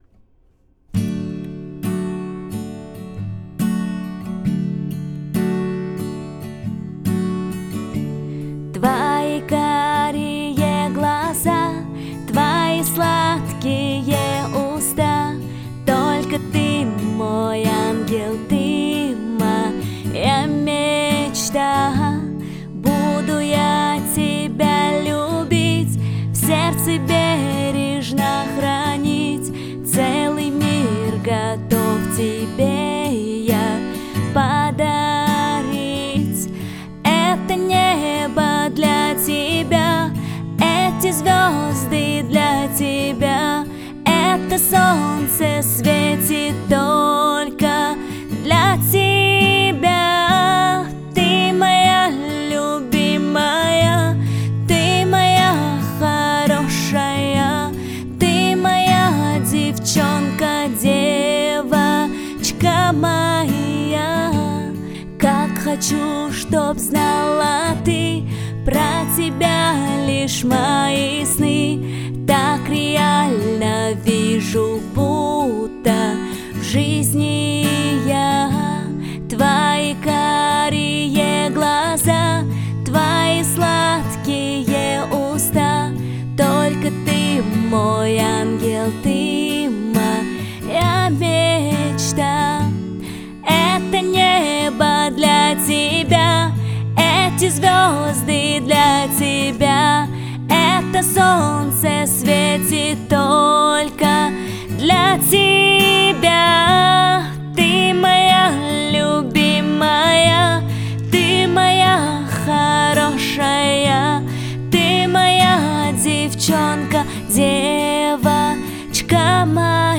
Гитара и голос. На скорую руку, без каких либо проб. Гитара отдельно, голос отдельно. минимум редактуры.